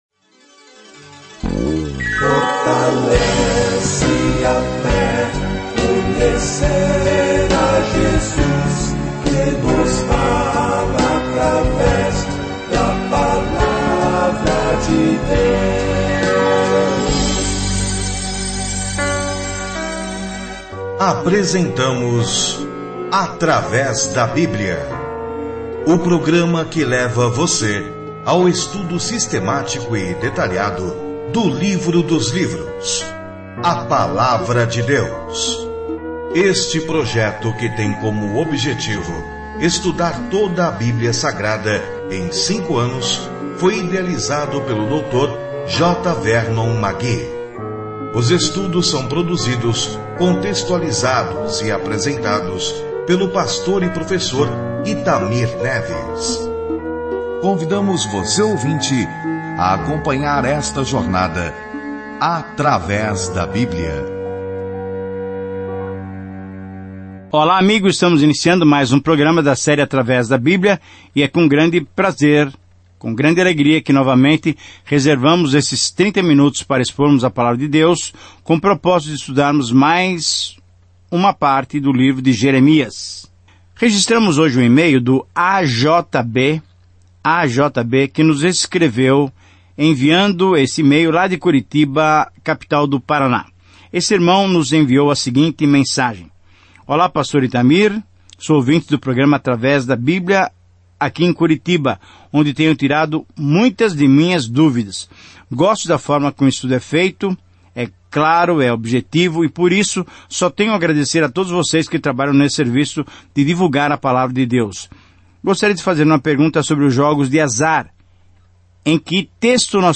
As Escrituras Jeremias 40:1-16 Dia 16 Começar esse Plano Dia 18 Sobre este Plano Deus escolheu Jeremias, um homem de coração terno, para transmitir uma mensagem dura, mas o povo não a recebeu bem. Viaje diariamente por Jeremias enquanto ouve o estudo em áudio e lê versículos selecionados da palavra de Deus.